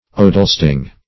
Search Result for " odelsthing" : The Collaborative International Dictionary of English v.0.48: Odelsthing \O"dels*thing\, n. [Norw. odel odal + ting parliament.] The lower house of the Norwegian Storthing.